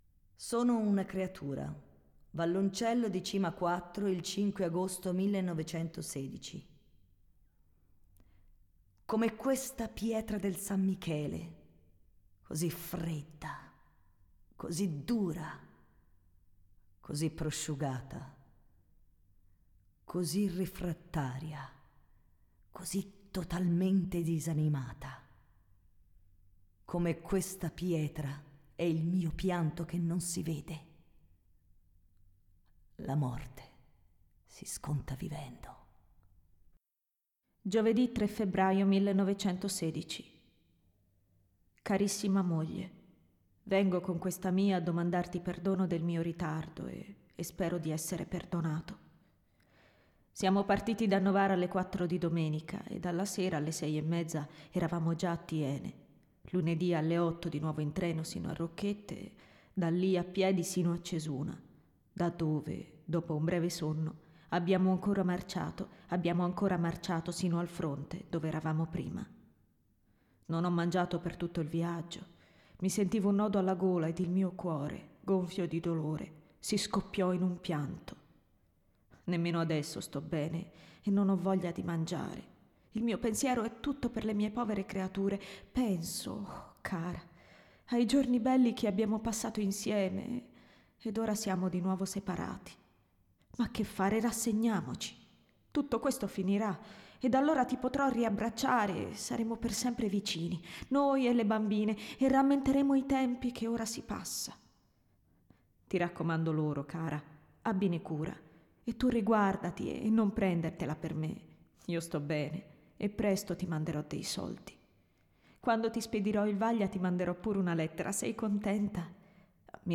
La registrazione è stata effettuata al Teatro del Maggio il 24, 29 settembre, 1, 2 ottobre 2015 in occasione del centenario della I guerra mondiale
voce recitante